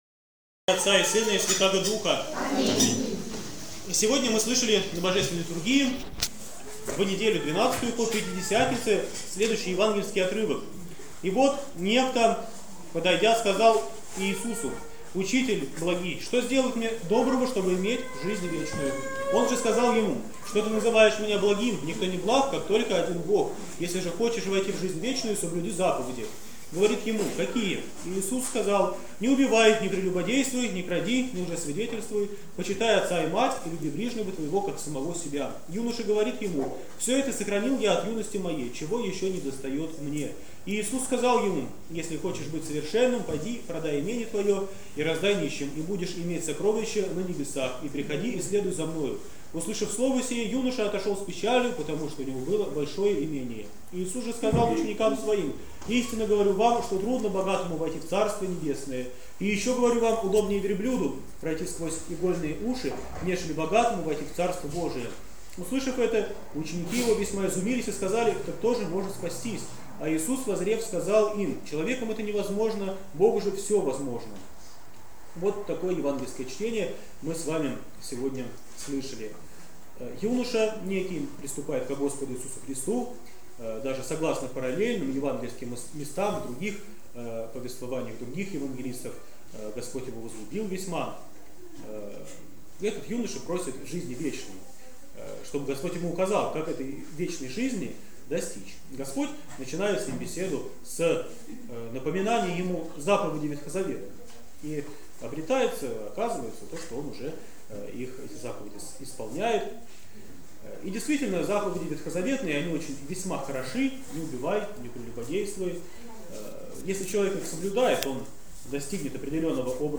ПРОПОВЕДЬ В НЕДЕЛЮ 12-Ю ПО ПЯТИДЕСЯТНИЦЕ